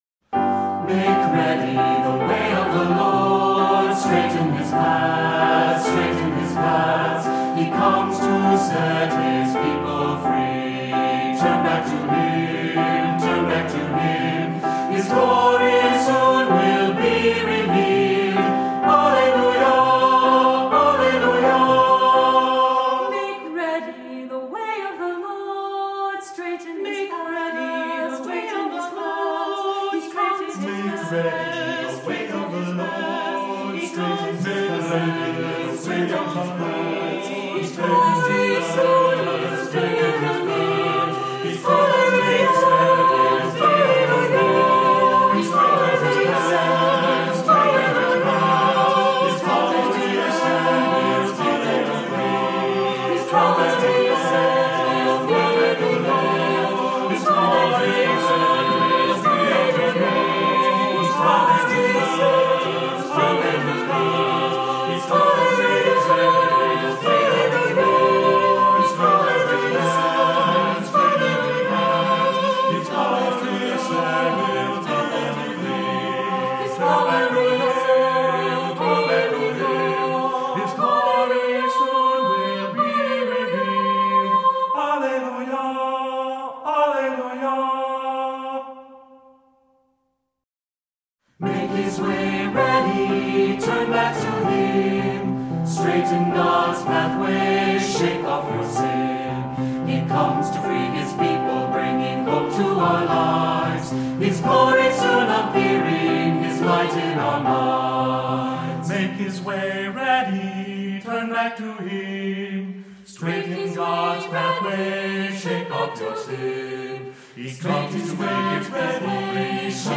Voicing: Unison choir; opt. descant; assembly